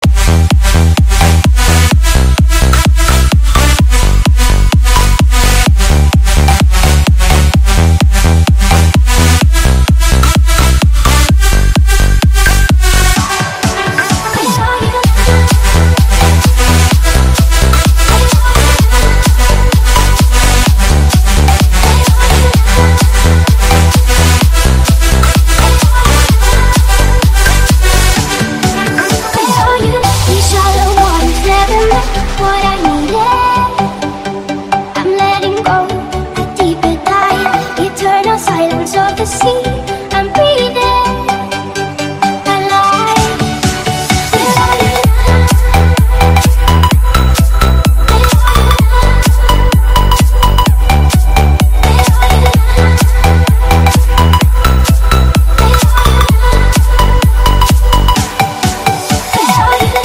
• Качество: 121, Stereo
женский вокал
dance
EDM
club
Bass
electro house
bounce